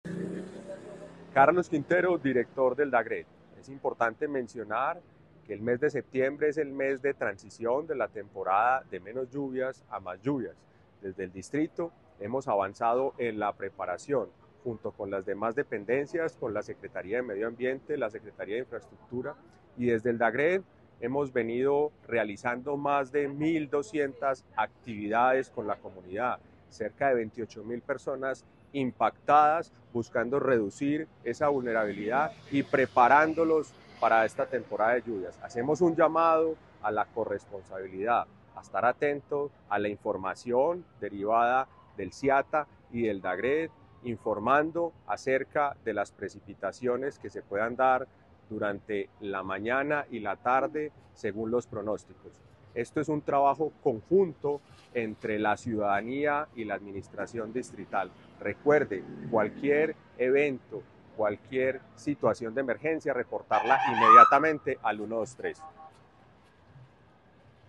Declaraciones del director del DAGRD, Carlos Andrés Quintero.
Declaraciones-del-director-del-DAGRD-Carlos-Andres-Quintero.-Segunda-temporada-de-lluvias-.mp3